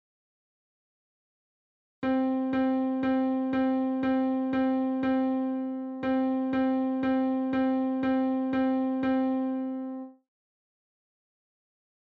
例えば、全部ドで弾くと、こうなります。